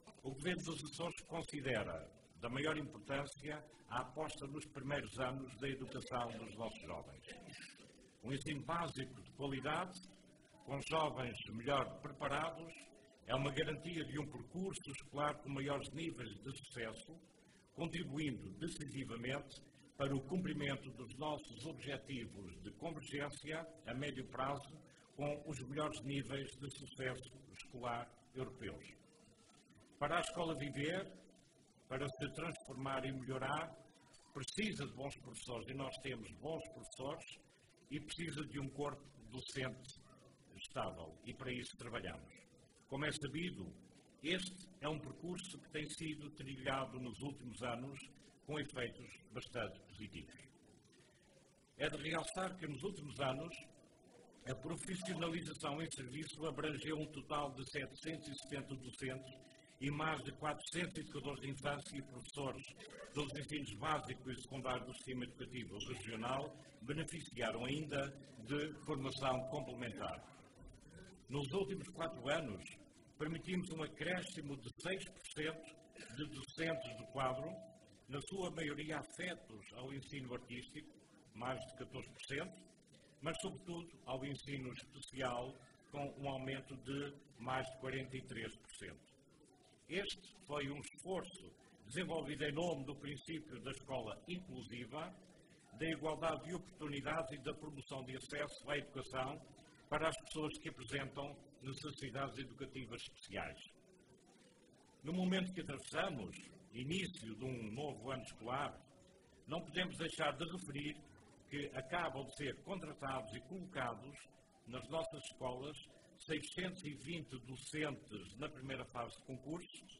O anúncio foi feito pelo Secretário Regional da Educação, Ciência e Cultura, Luiz Fagundes Duarte, na cerimónia de inauguração da nova Escola Básica e Jardim de Infância da Ribeirinha, na ilha Terceira, um investimento de cerca de 4,2 milhões de euros, suportados pelo executivo açoriano e pela Câmara Municipal de Angra do Heroísmo.